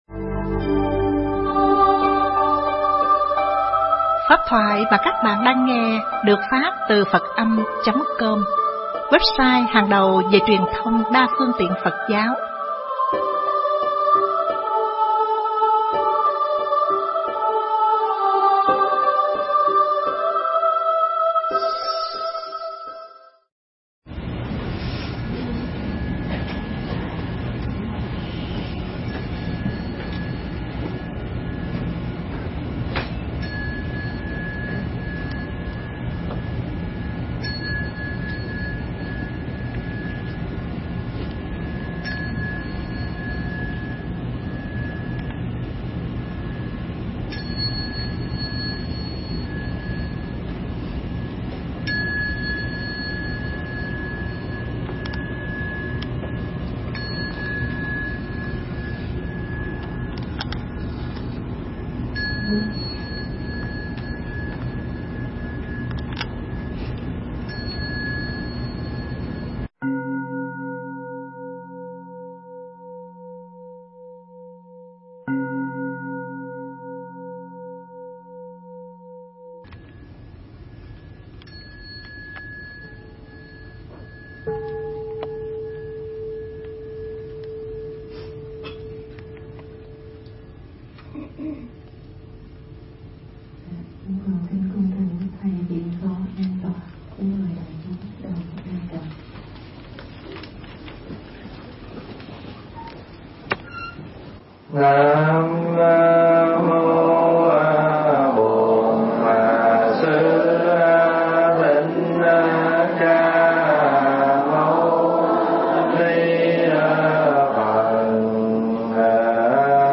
Nghe Mp3 thuyết pháp Lục Độ – Thần Thông Phần 1